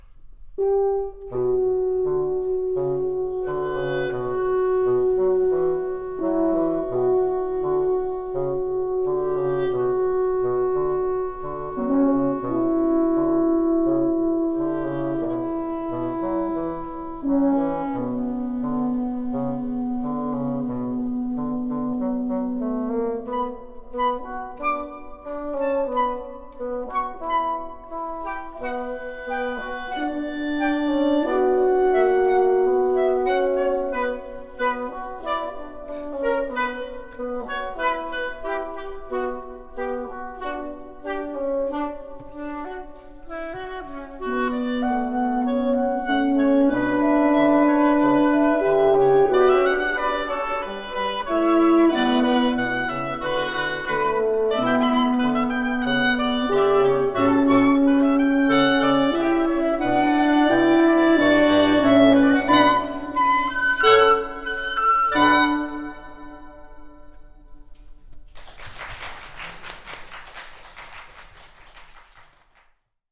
Wind Quintet Miniature
4. Genre: New classical
No extended techniques or experimentation with wind quintet instruments in this work, just a quick entry into the interaction of wind instruments using a very traditional Western musical language.
Recorded 18 March 2011, Queens College.
wind-quintet-miniature.mp3